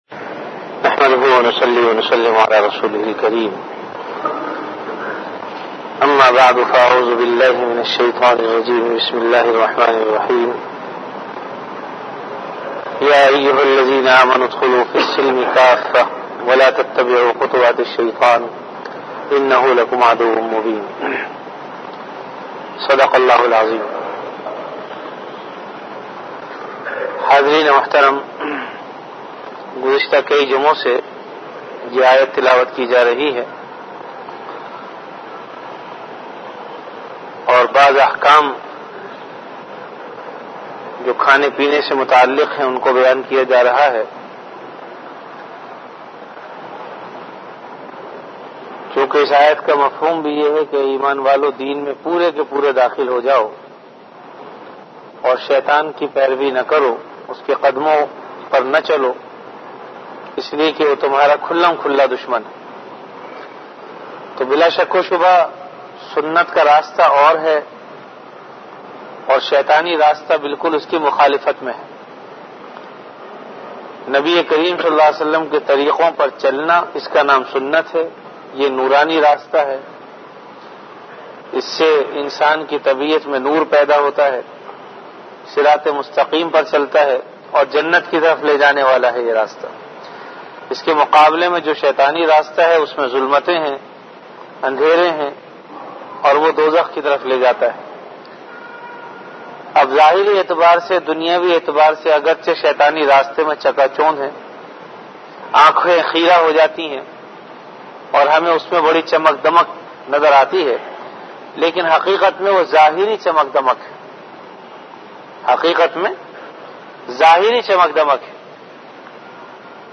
Delivered at Jamia Masjid Bait-ul-Mukkaram, Karachi.
Bayanat · Jamia Masjid Bait-ul-Mukkaram, Karachi
After Isha Prayer